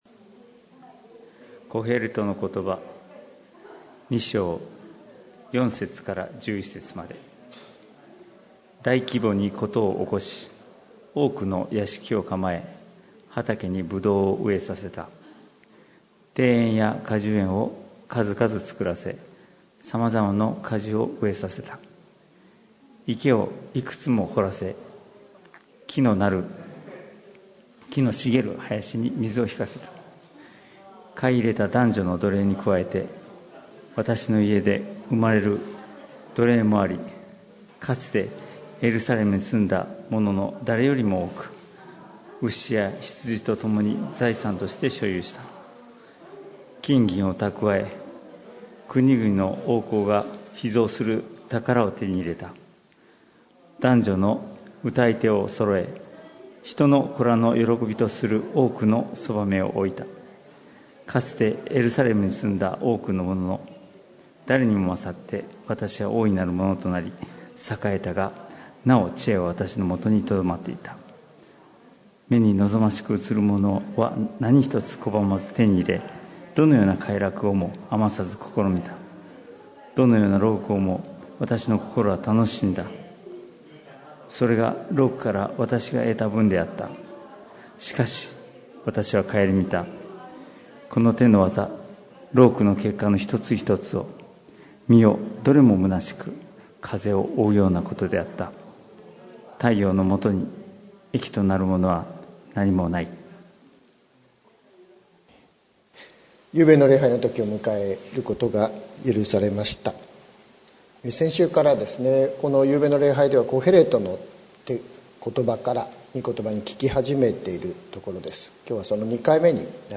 2020年08月30日朝の礼拝「これ上ない恵み」関キリスト教会
説教アーカイブ。